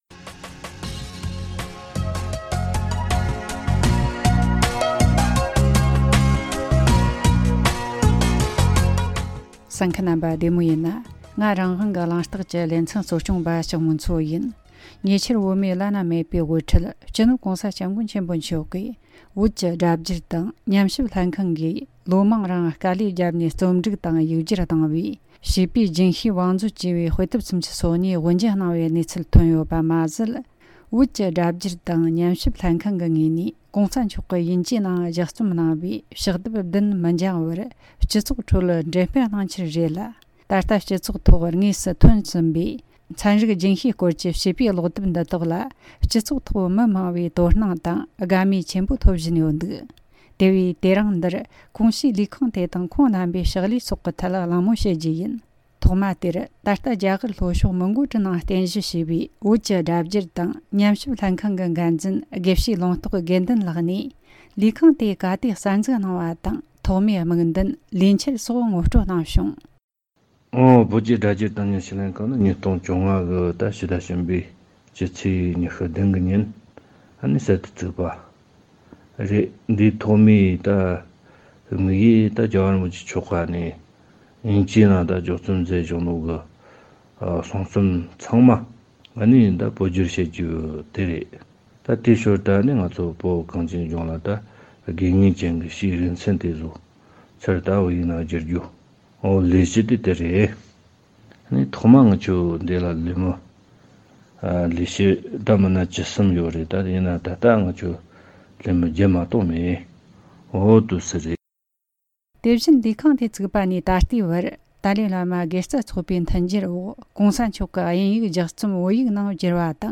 འབྲེལ་ཡོད་མི་སྣ་དང་གླེང་མོལ་ཞུས་པར་གསན་རོགས་གནོངས།